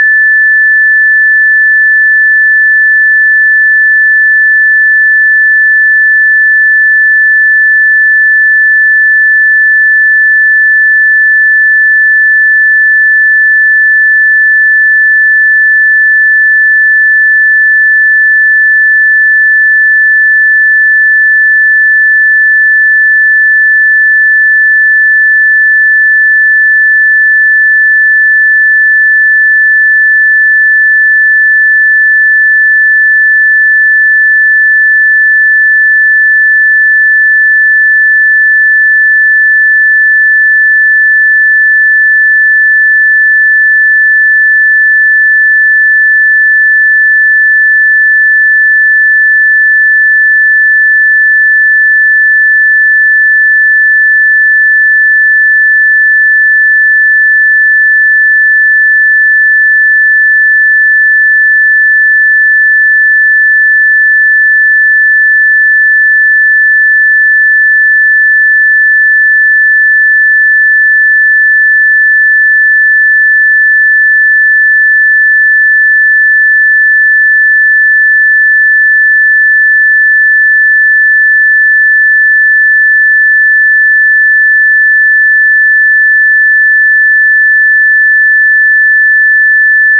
We reversed: I used audacity to produce a sound file containing just an audio sine wave of 1750 Hz frequency.
Here comes that audio file, just one-and-a-half minutes of 1750 Hz sine wave: